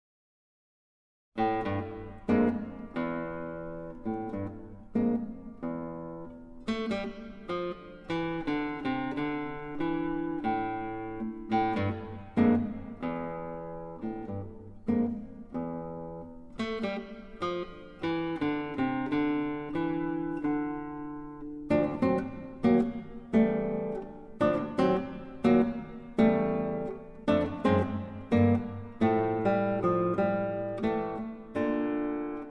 Guitar
Narrator